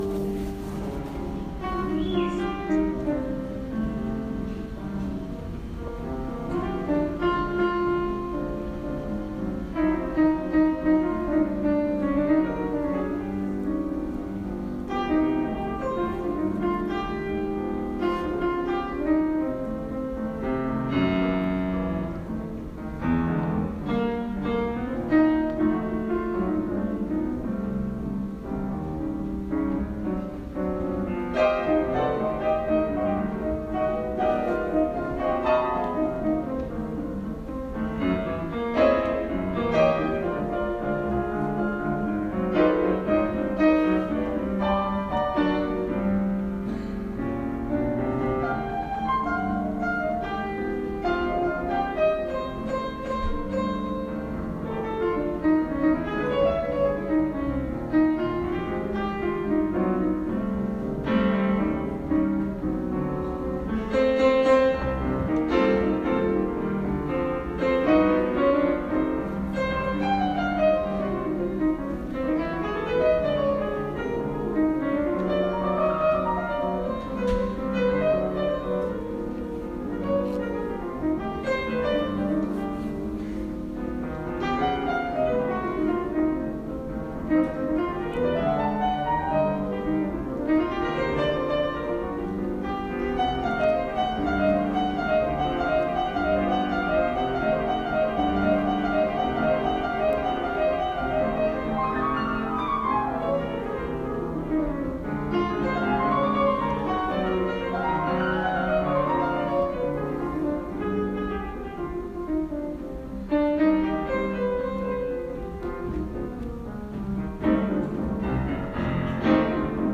el pianista que iba a estar es un jazzista francés muy bacán
súper mega standards de jazz